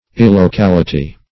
Illocality \Il`lo*cal"i*ty\, n. Lack of locality or place.